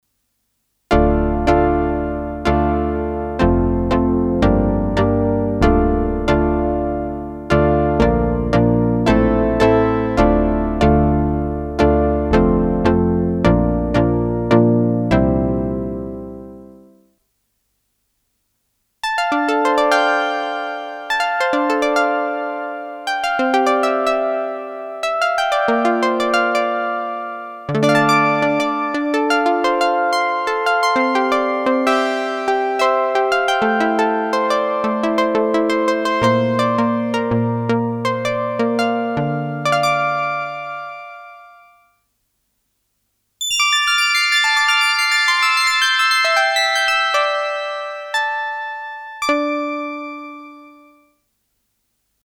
Um noch mal auf den Ausgangspost zurückzukommen, werfe ich mal ein Klangbeispiel in den Raum. 2 Sägezahnoszillatoren gleicher Tonhöhe, 4-Pol-Filter, Hüllkurvenmodulation. Über alle Oktaven gespielt. Für meine Ohren kann sowas kein moderner Polysynth: Mit einem einfachen Patch so plastisch-direkt, quasi-akustisch, organisch, druckvoll, offen, stabil und lebendig klingen.